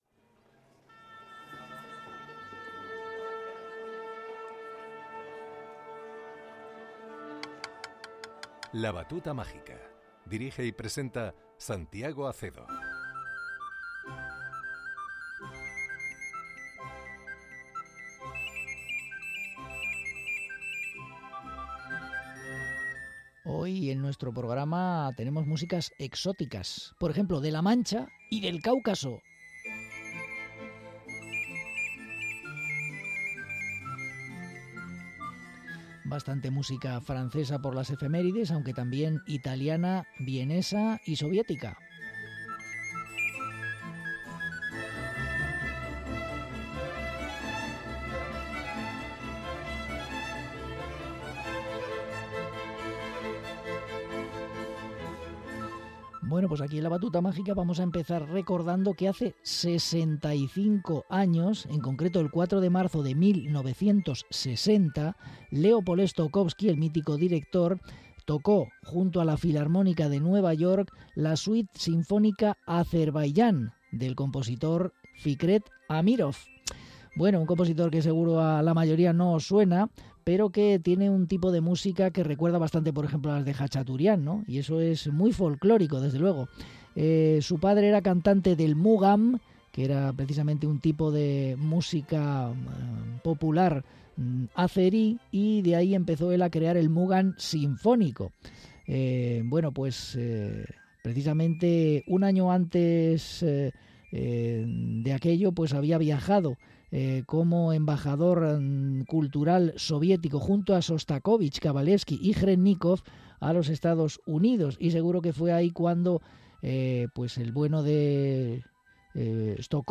romanza para Violonchelo y Orquesta